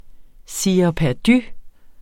Udtale [ ˌsiːɐ̯ pæɐ̯ˈdy ]